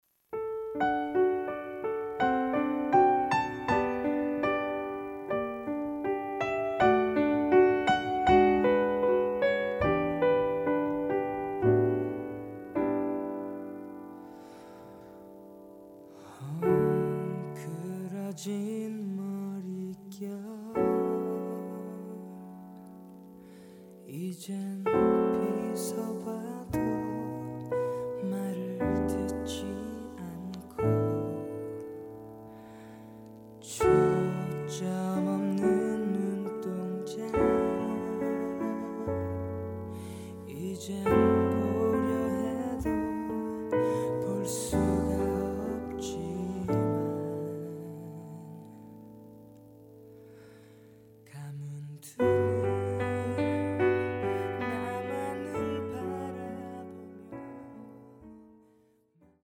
음정 원키 4:39
장르 가요 구분 Voice Cut
Voice Cut MR은 원곡에서 메인보컬만 제거한 버전입니다.